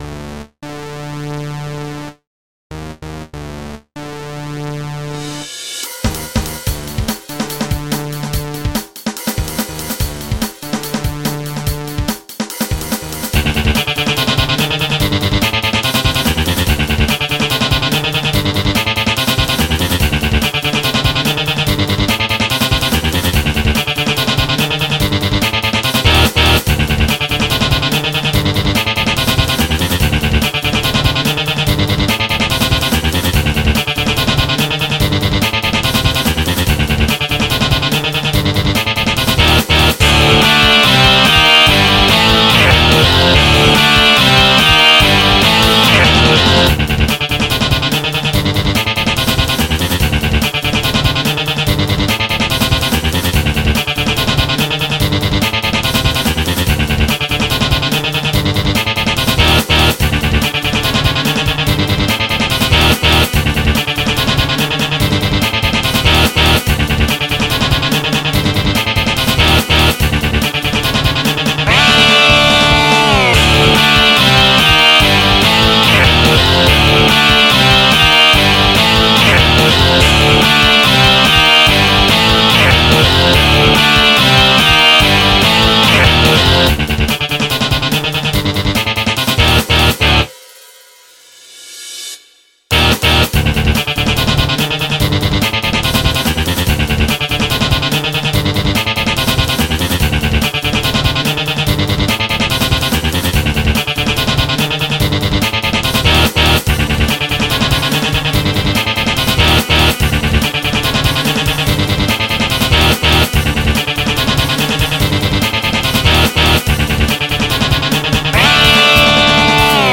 MIDI 47.9 KB MP3